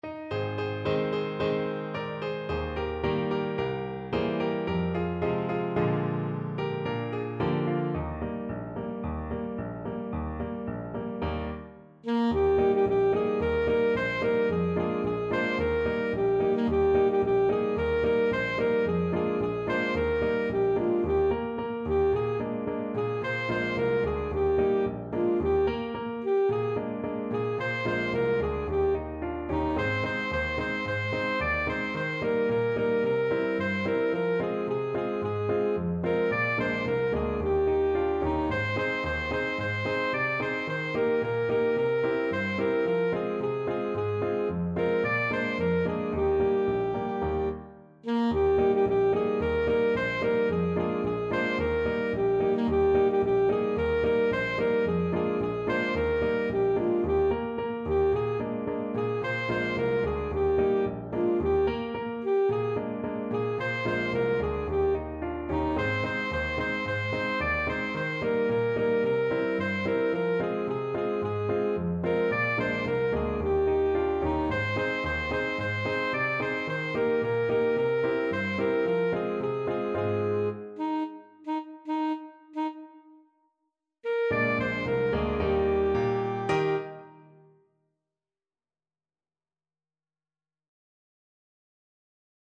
Alto Saxophone version
Alto Saxophone
2/4 (View more 2/4 Music)
Allegro =c.110 (View more music marked Allegro)
Bb4-Eb6
Free Traditional Sheet music